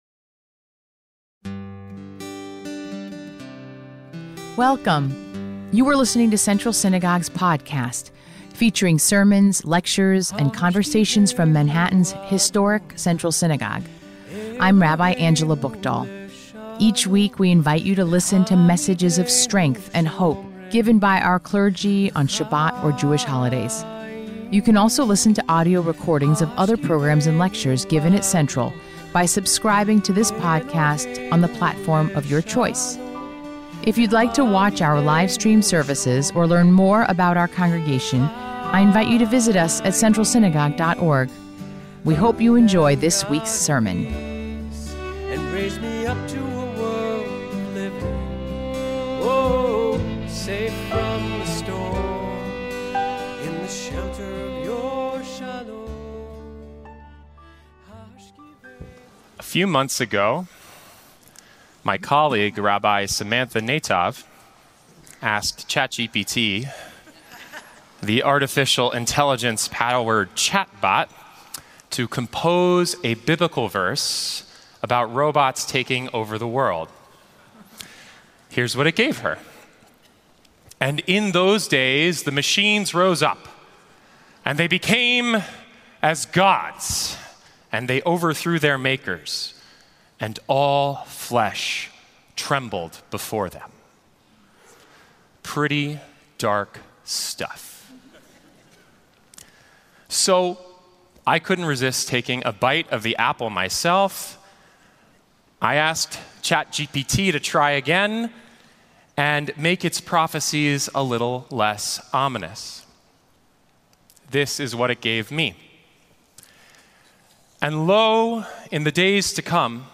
MEDITATION
Weekly Meditation